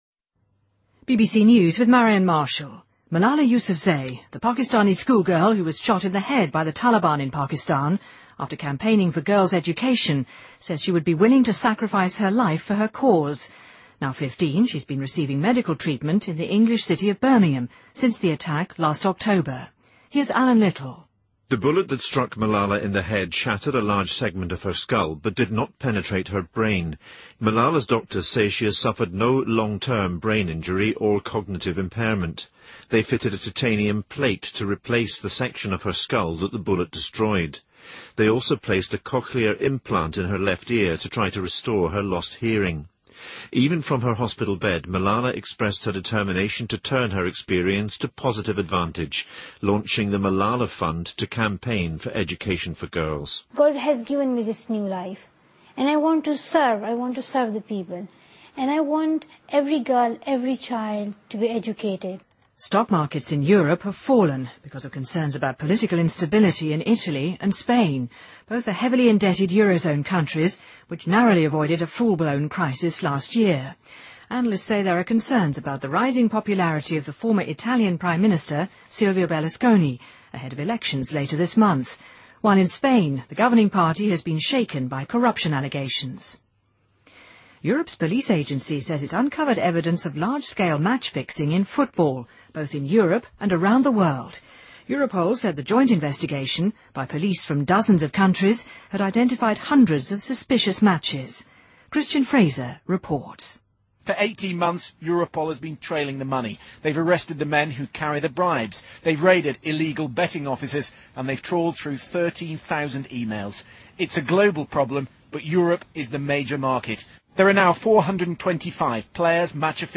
BBC news,欧洲刑警组织在欧洲和全世界发现大规模比赛操纵的证据